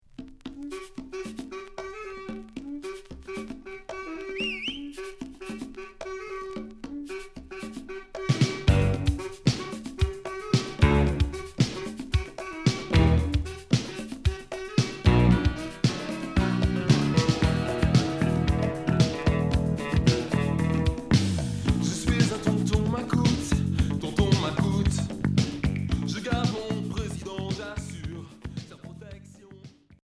Rock new wave Deuxième 45t retour à l'accueil